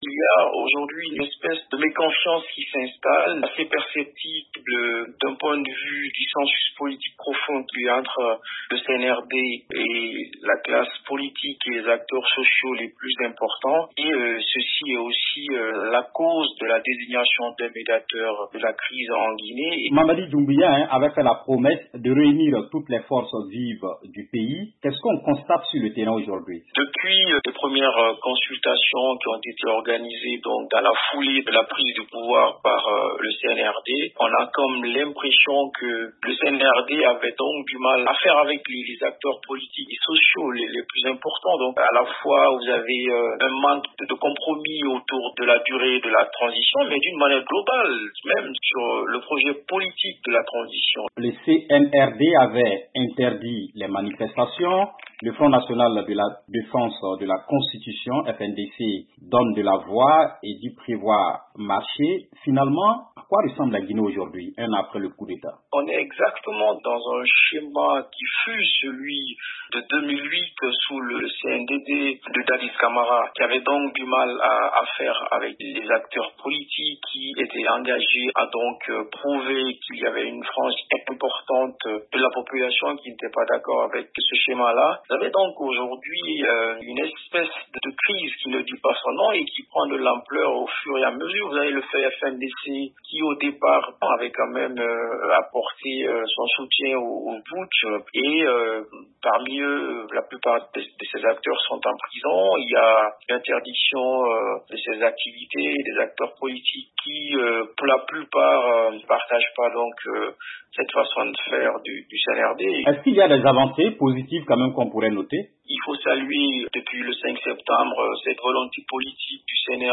politologue et analyste politique.